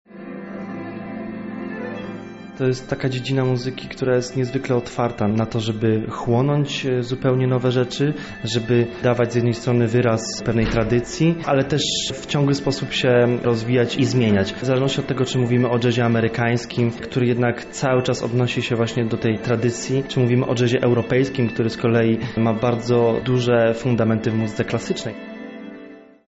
Czym jest „jazz”? Mówi Adam Bałdych, jeden z najwybitniejszych muzyków polskiej sceny.